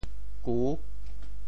潮州 neng5 gu6 潮阳 neng5 gu6 潮州 0 1 潮阳 0 1